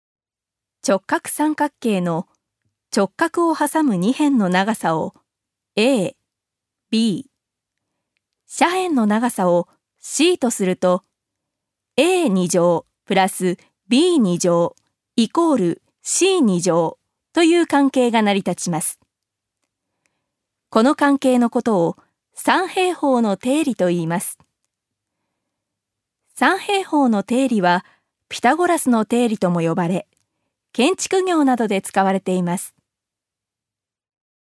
ジュニア：女性
ナレーション４